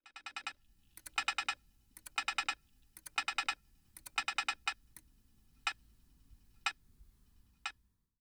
Ampel
Das Geräusch, welches eine Grazer Fussgängerampel zusätzlich zu dem optischen signal, besonders für Sehbehinderte, ausgibt, ab dem Zeitpunkt an dem das baldige Rotwerden angekündigt wird.
ampel